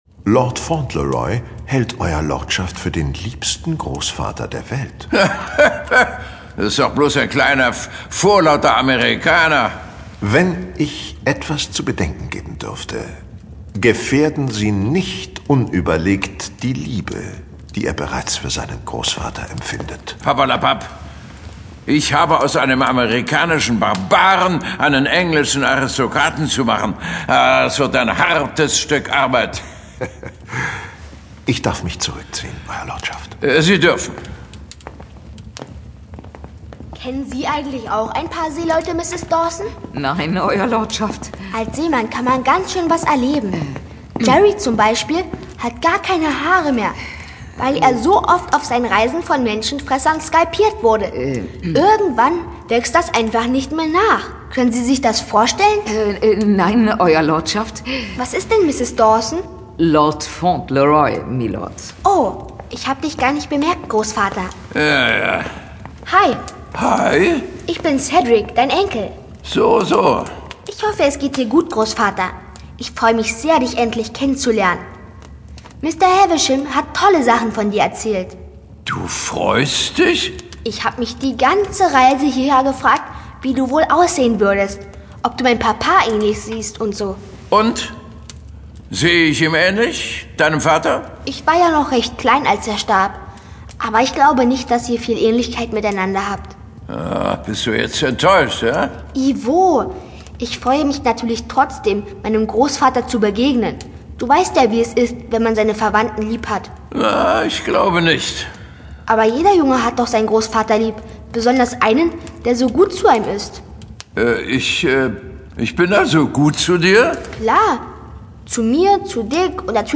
Titania Special 2. Hörspiel. Empfohlen ab 8 Jahren